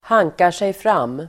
Uttal: [hangkar_sejfr'am:]